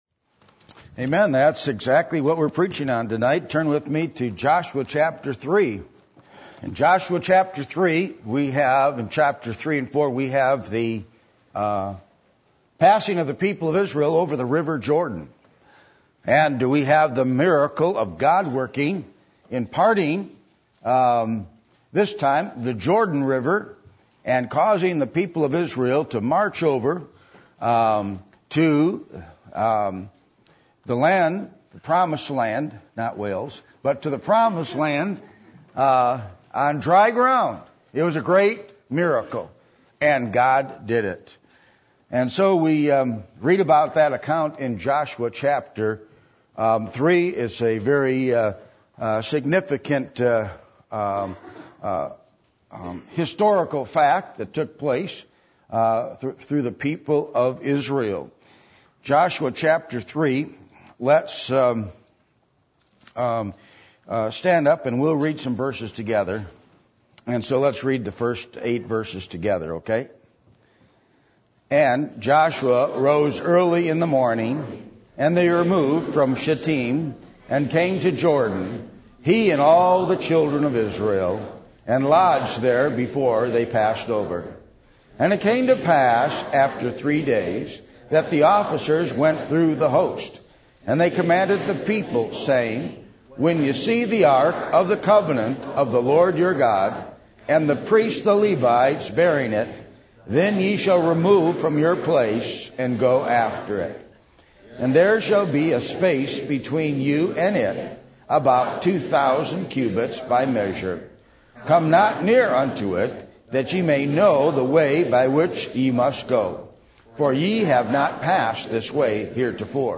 Joshua 3:1-8 Service Type: Sunday Evening %todo_render% « Is Jesus Perfectly Holy Or Relatively Holy?